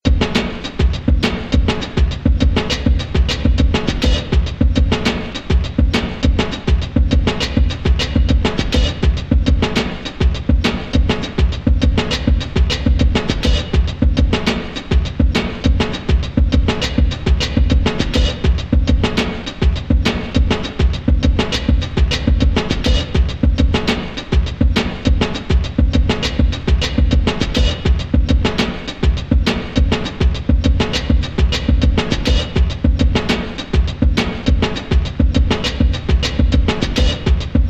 Batterie
rythme.mp3